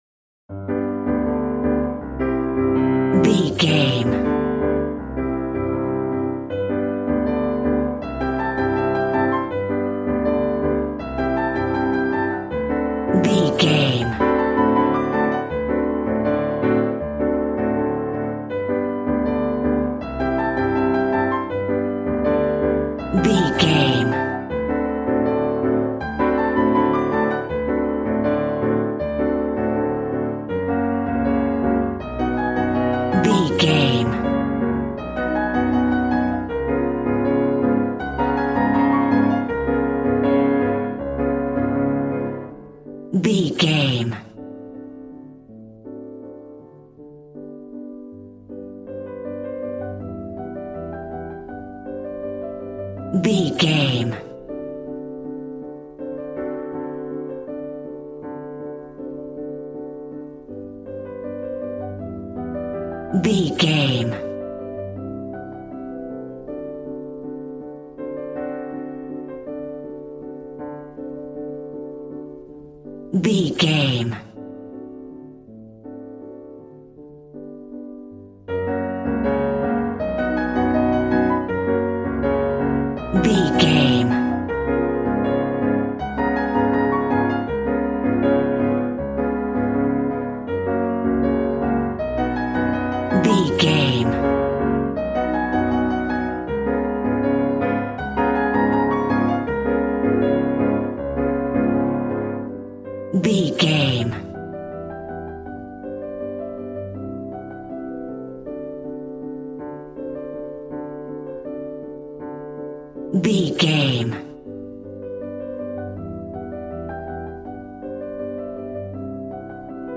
Aeolian/Minor
passionate
acoustic guitar